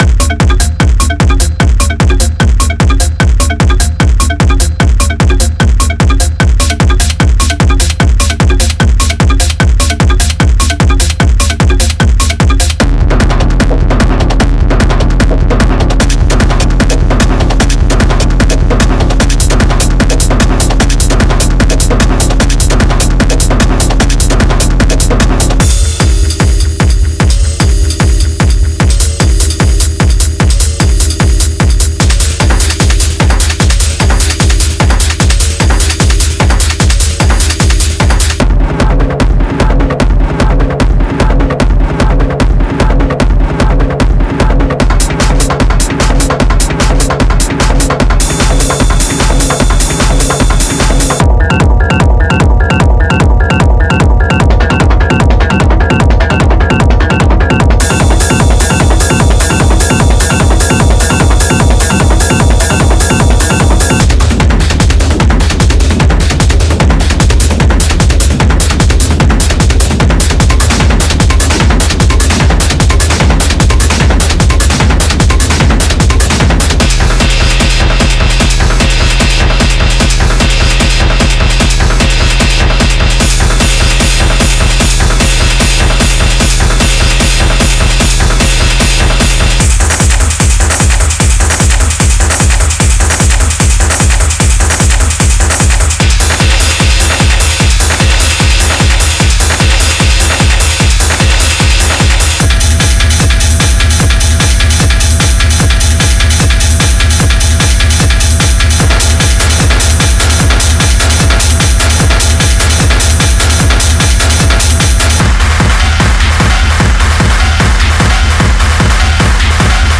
Genre:Industrial Techno
この24ビットのオーディオコレクションは、150BPMに設定されています。
ぜひヘビーなデモトラックをチェックしてください。
59 Techno Kick Loops
122 Percussion Loops